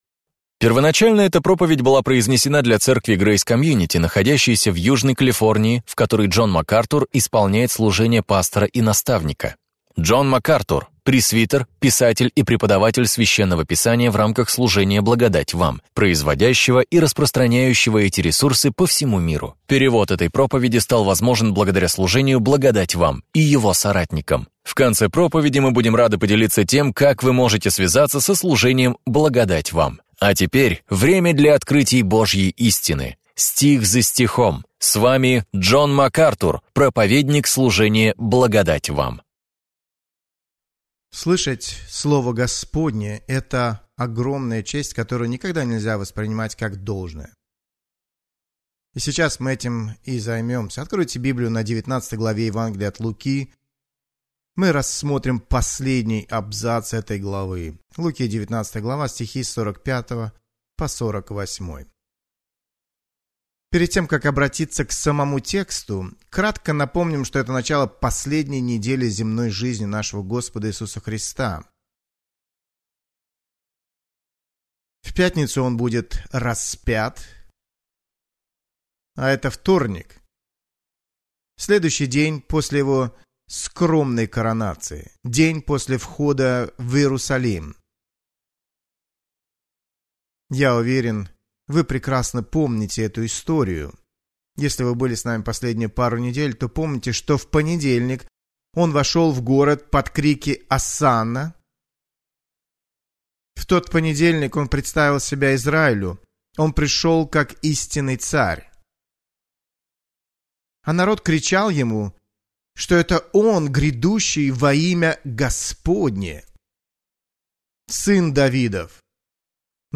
Об этом и пойдет речь в проповеди Джона Макартура «Как общаться с еретиком». Вы увидите изнанку одного из острых конфликтов Христа с набожными врагами истины и Евангелия.